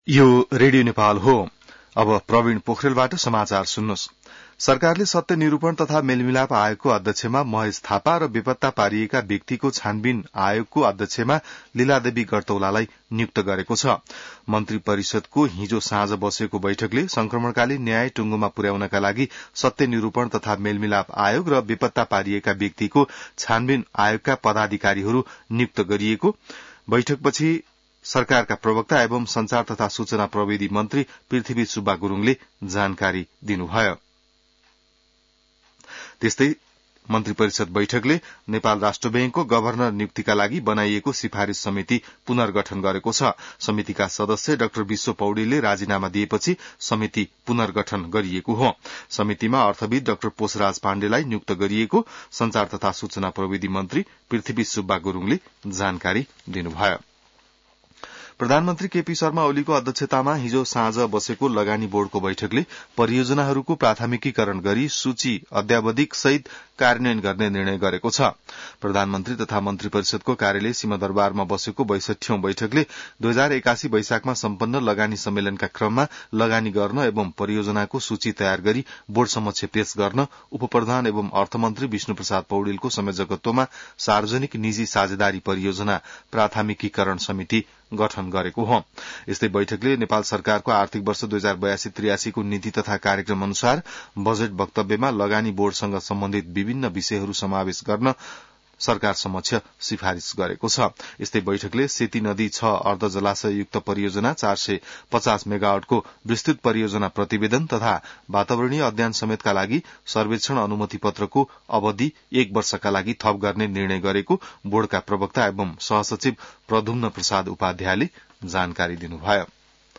बिहान ६ बजेको नेपाली समाचार : १ जेठ , २०८२